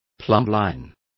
Also find out how plomada is pronounced correctly.